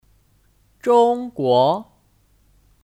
中国 Zhōngguó (Kata benda): China